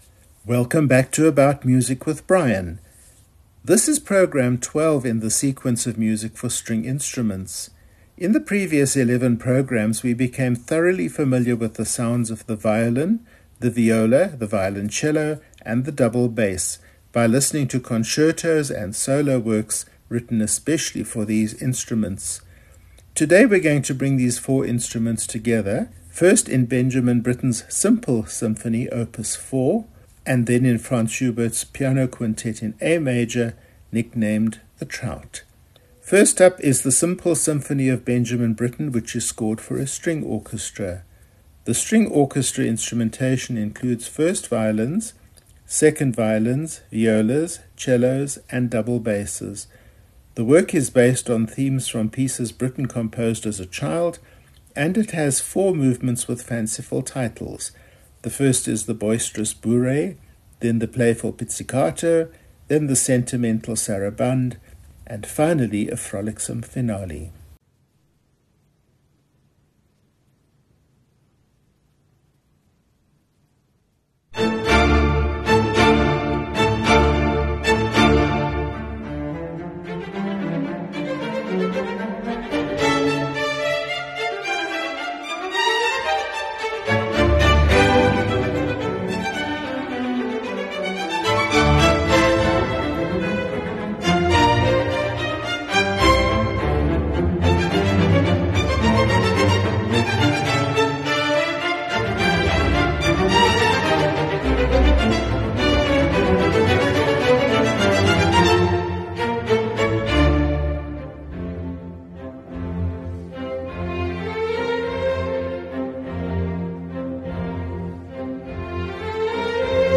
Programme 107 Music for the full string ensemble.
Today we bring these four instruments together in: Benjamin Britten – Simple Symphony, Op. 4 where the string orchestra instrumentation includes first violins, second violins, violas, cellos, and double basses.